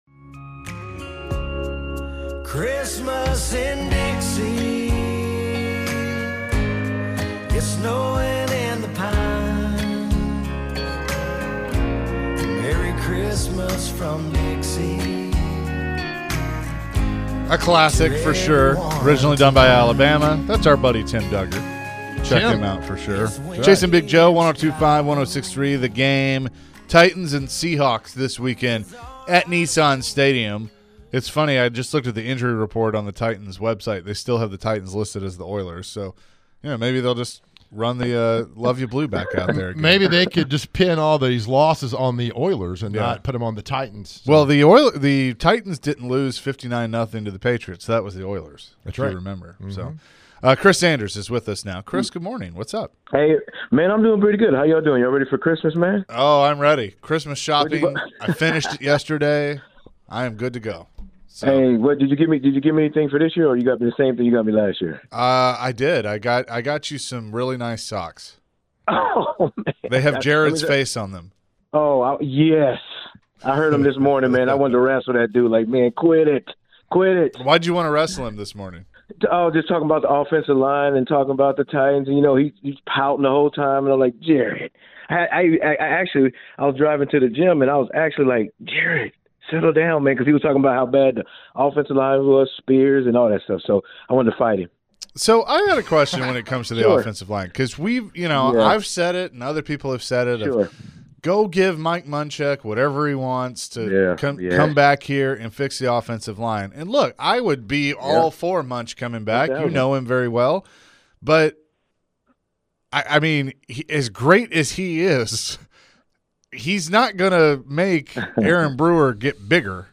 Forever Titans WR Chris Sanders joined the show and talked about the Titans talent on the roster from wide receivers and offensive linemen. Chris also shared his thoughts on the potential of Ryan Tannehill getting the start Sunday.